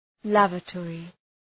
{‘lævə,tɔ:rı}
lavatory.mp3